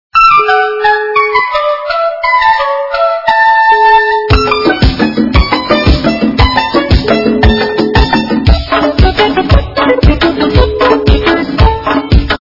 » Звуки » другие » Звонок